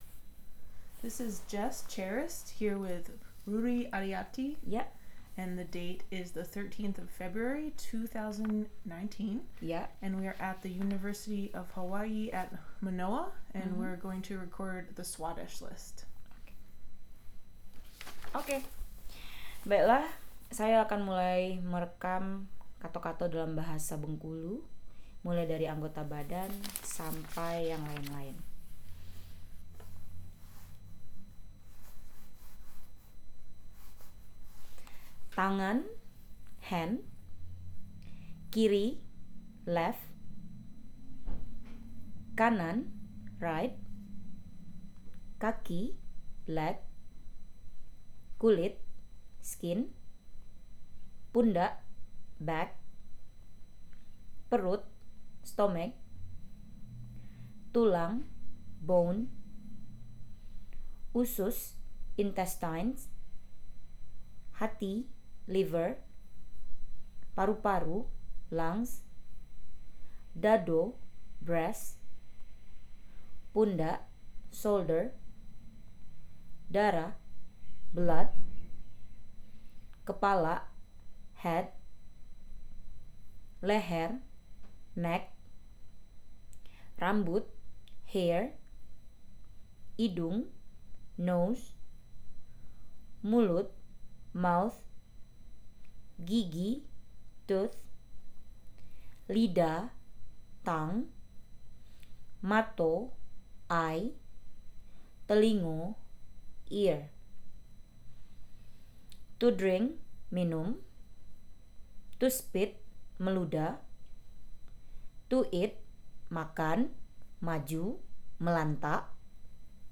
dc.descriptionSwadesh list audio recording
dc.description.regionBengkulu, Indonesia